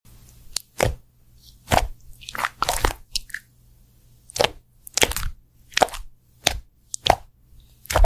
🍉🤏 Squishing Melon Slice! Juicy sound effects free download